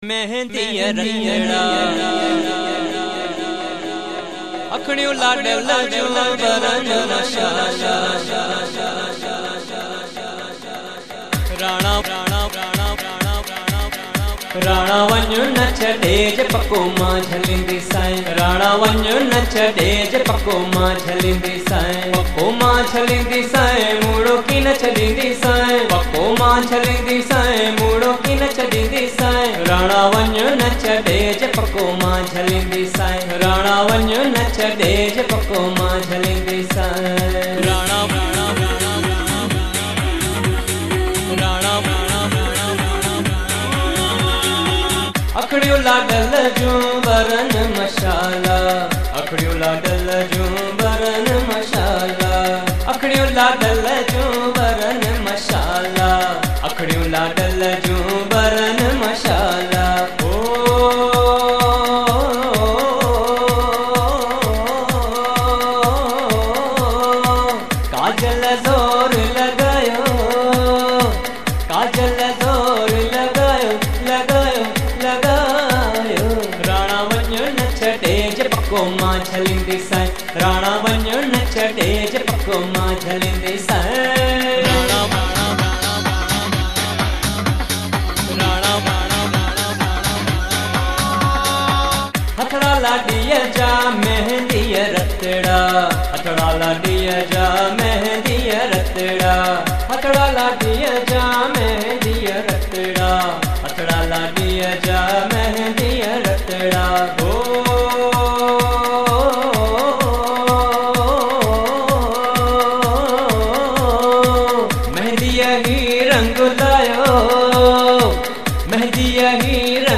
Sindhi Songs for Lada (Ladies Sangeet)